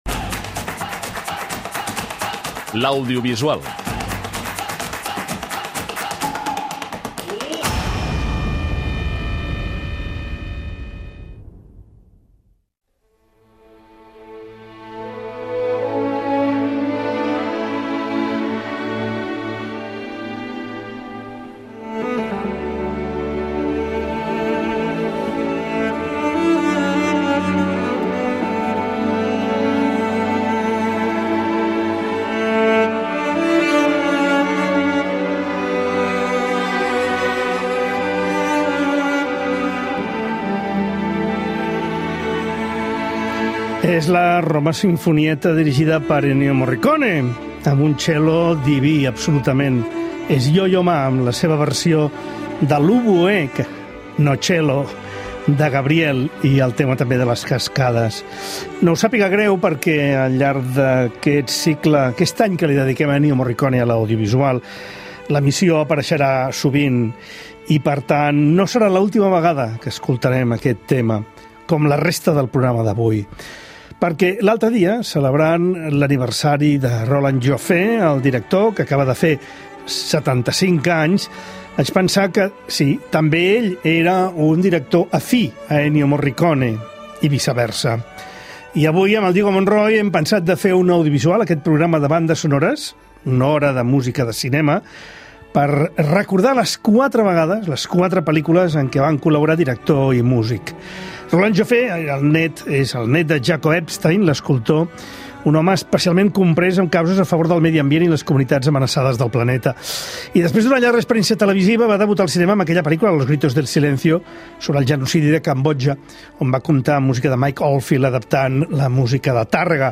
Aix� que podrem sentir les composicions musicals dels films